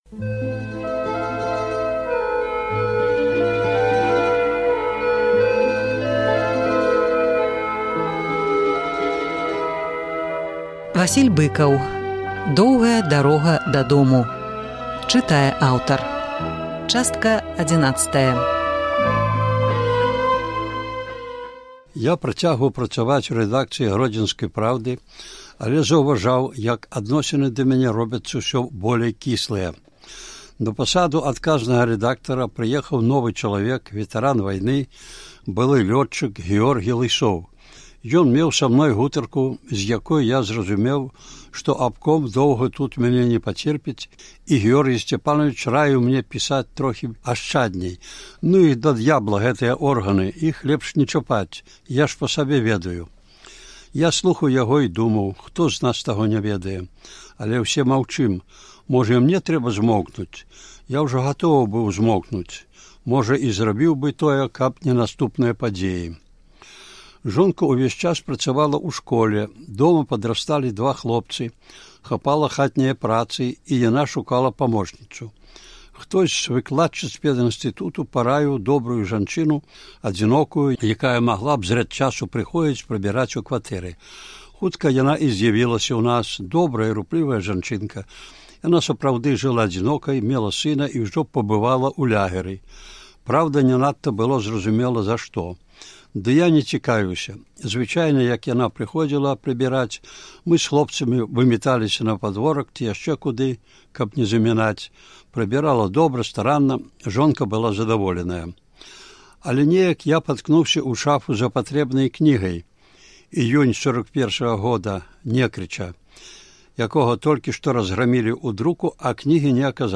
Чытае аўтар.